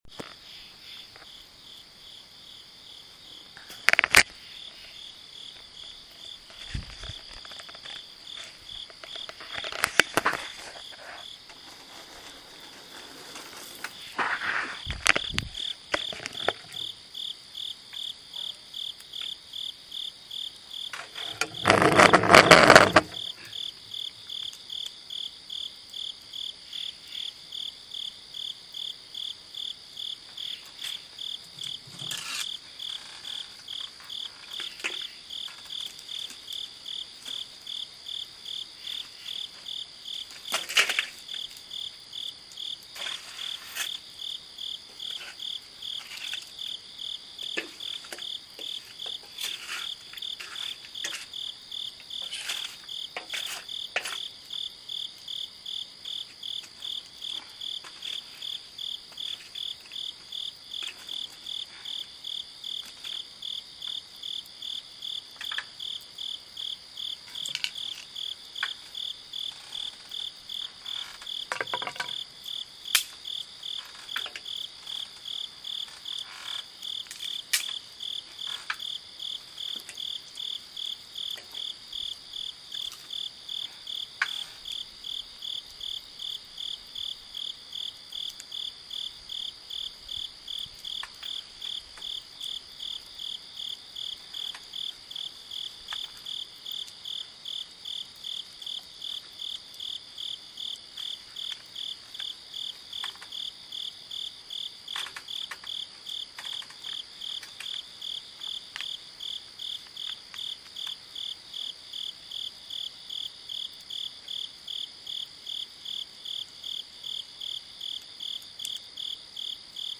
With this poem I've included the sounds of the inspiring night in the backyard among the stone angel in which I scrawled this in the dark. Play the recording along with the reading and gain a large slice of the Kentucky night for your ear-bones.